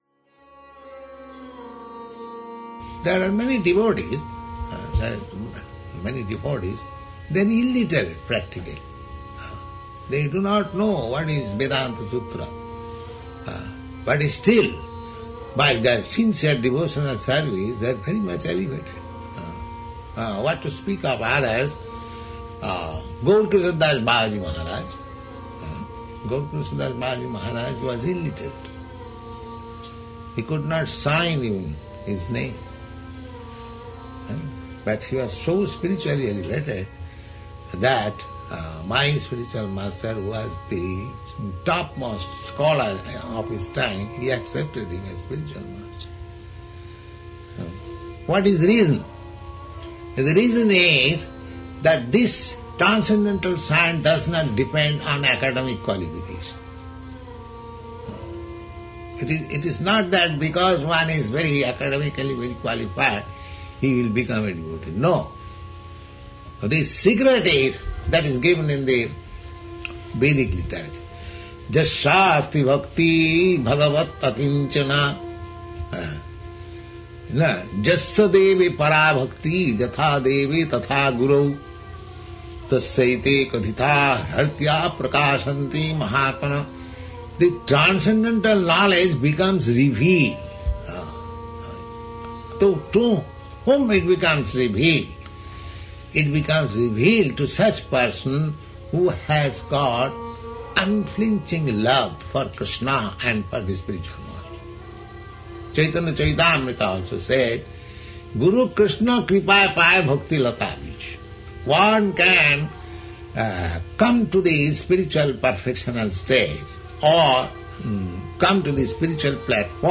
(740313 – Lecture CC Adi 07.91-2 – Vrndavana)